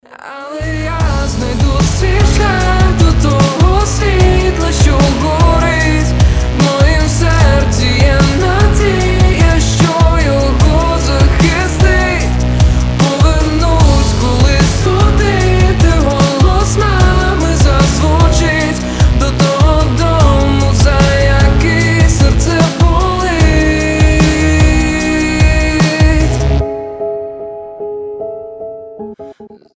поп , лирика